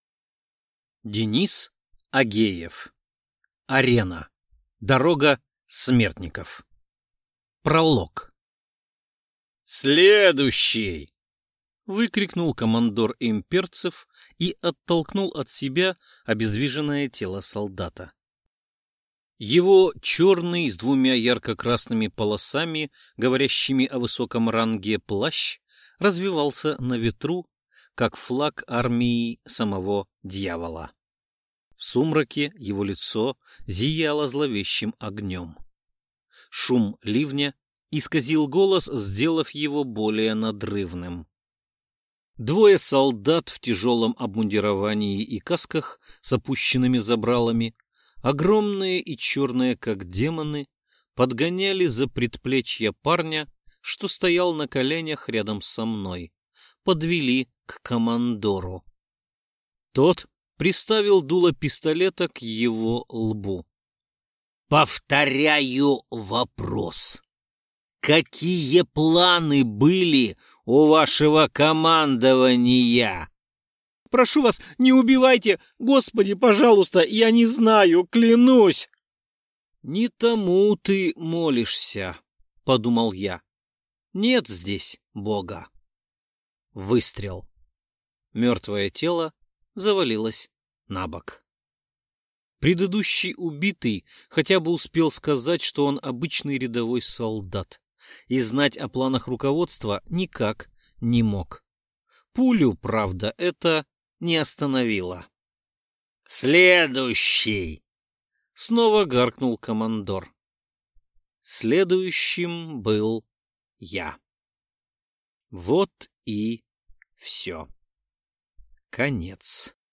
Аудиокнига Арена: Дорога смертников | Библиотека аудиокниг